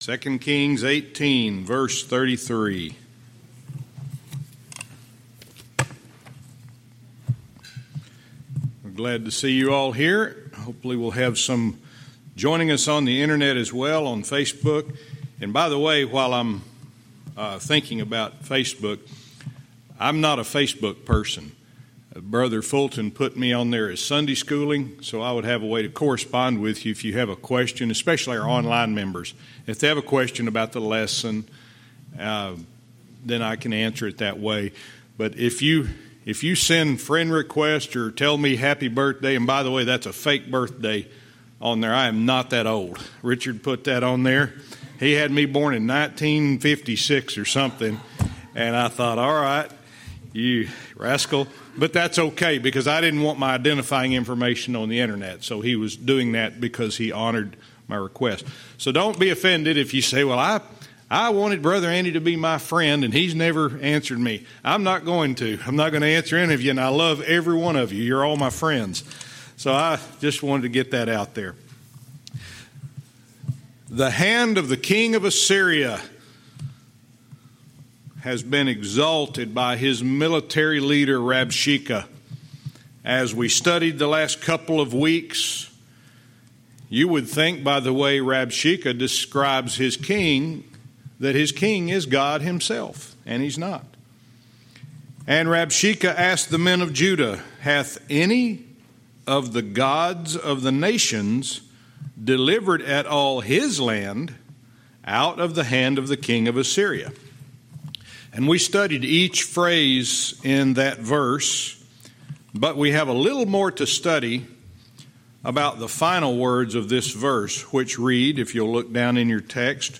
Verse by verse teaching - 2 Kings 18:33-35